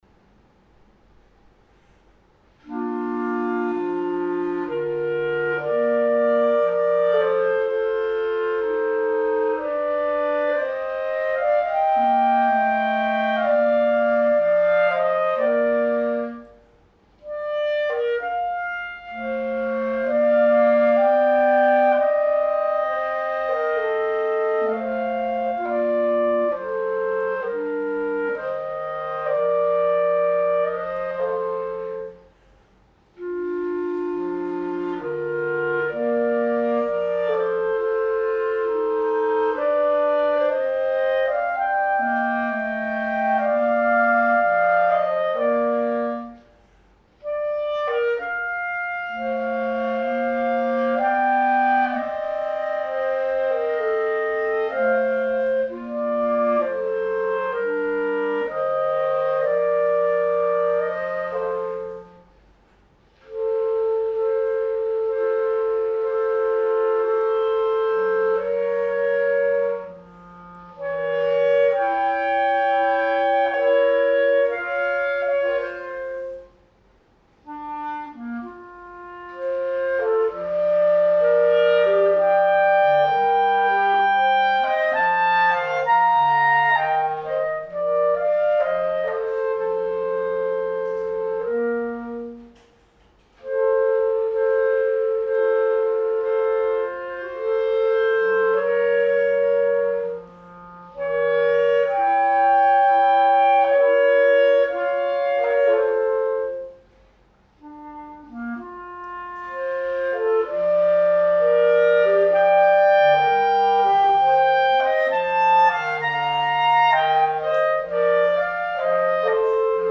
（録音の1st Partです。）
コシはあるけれども反応がよいリードで、少し線は細いがはっきり目の音。太さより柔軟性が前に出ている感じ？
楽器 Buffet Crampon Festival
マウスピース Alexander Willscher 40B
リード Vandoren V12 3
録音機材 Olympus LS-10
録音場所 、、、調理室、、、^^;
録音環境 2mくらい離れたところで録音